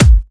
• Raw Disco Steel Kick Drum Sound A Key 558.wav
Royality free kick drum single shot tuned to the A note. Loudest frequency: 901Hz
raw-disco-steel-kick-drum-sound-a-key-558-Pj9.wav